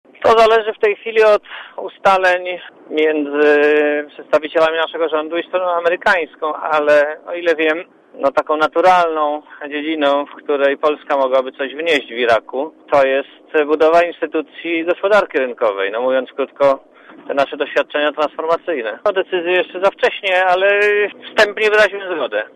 Mówi Marek Belka (180Kb)